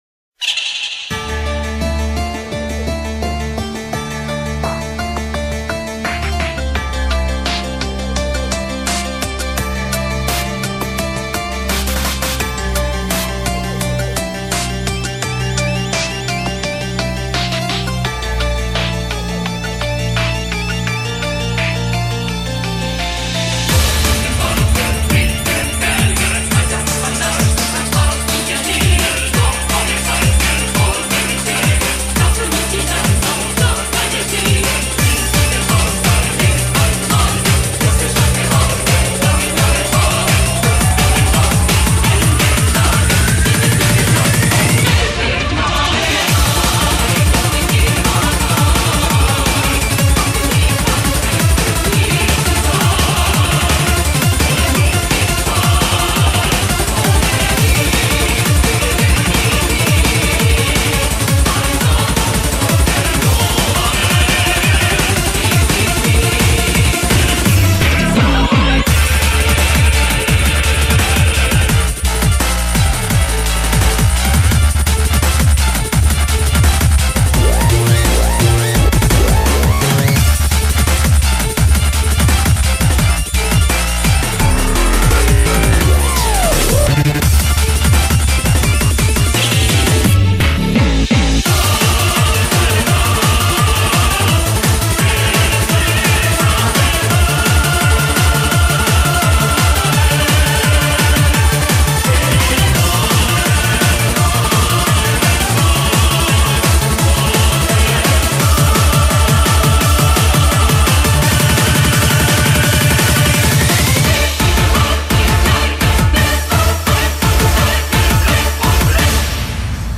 BPM85-340
Audio QualityPerfect (High Quality)
Comentarios[DARK OPERA REMIX]